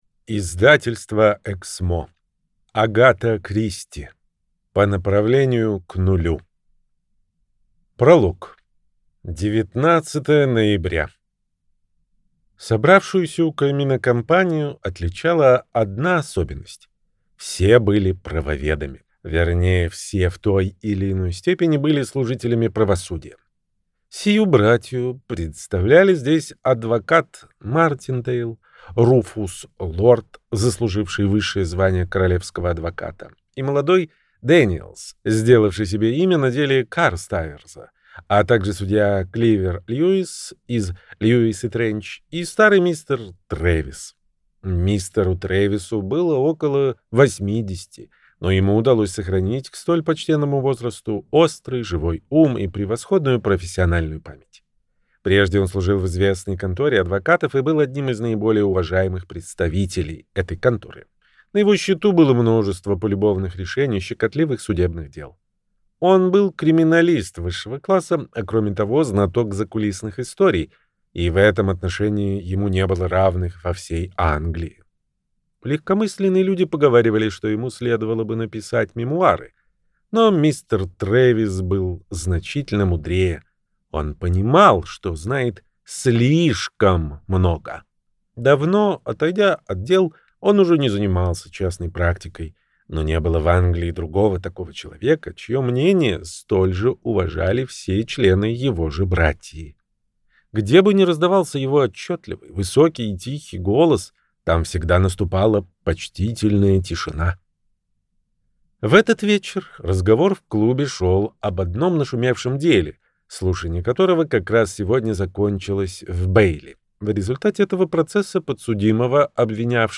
Аудиокнига По направлению к нулю - купить, скачать и слушать онлайн | КнигоПоиск